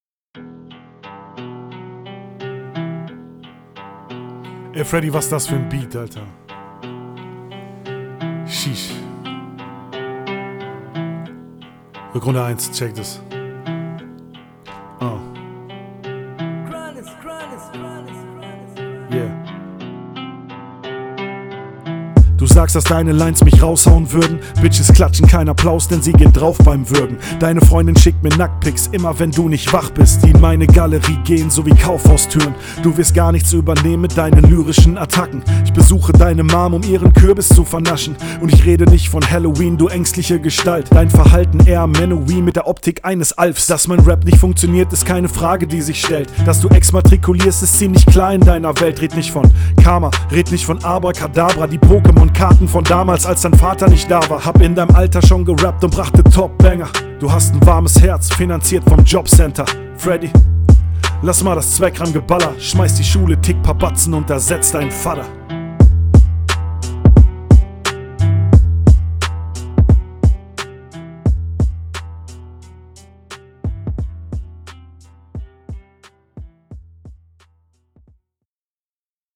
Wie erwartet, nimmst du den Beat stimmlich Volley!
Deine Stimme kommt im Vergleich zum Gegner besser auf dem Beat.